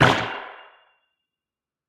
Minecraft Version Minecraft Version 1.21.4 Latest Release | Latest Snapshot 1.21.4 / assets / minecraft / sounds / entity / glow_squid / hurt4.ogg Compare With Compare With Latest Release | Latest Snapshot
hurt4.ogg